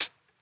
002_ODDMS_Hat_02.wav